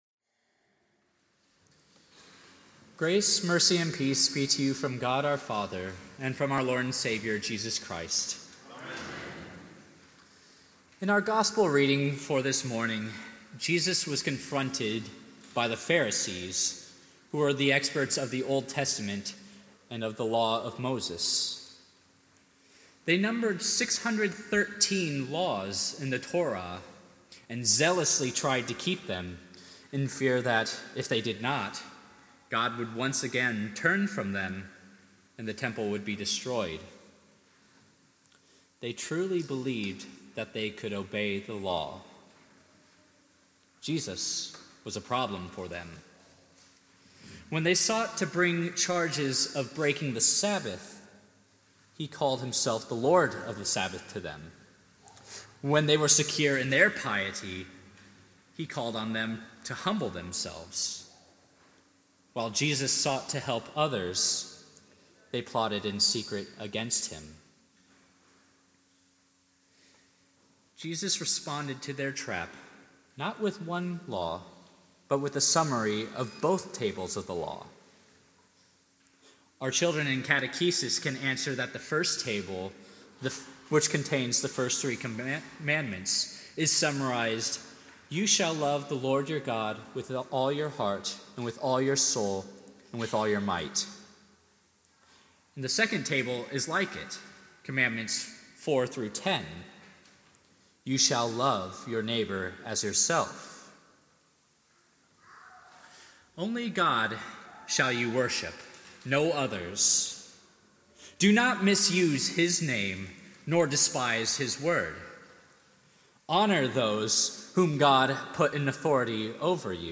Eighteenth Sunday after Trinity